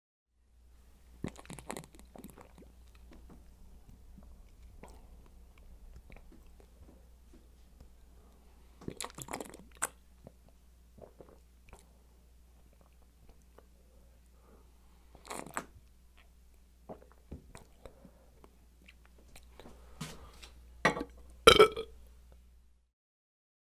Beer Drinking Sound Effect Free Download
Beer Drinking